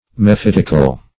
Mephitic \Me*phit"ic\, Mephitical \Me*phit"ic*al\, a. [L.